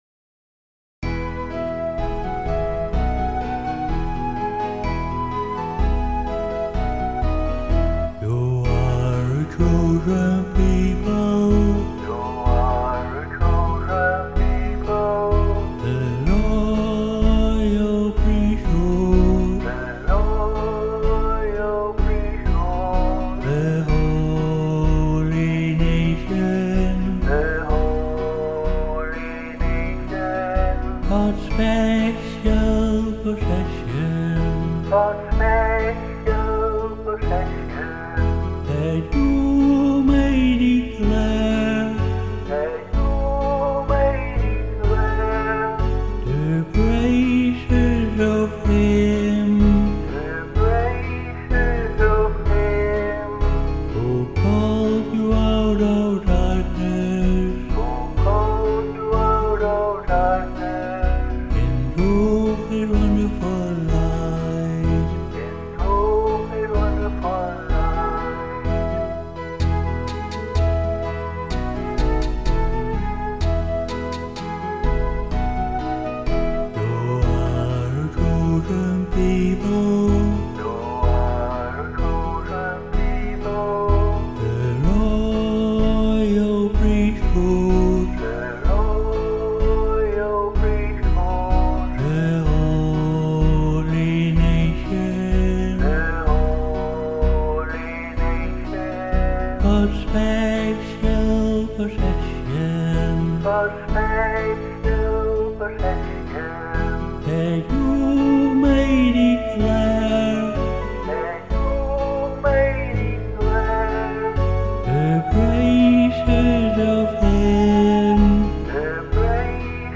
1 Peter 2:9 (NIV),  a 2-part echo song
voice and guitar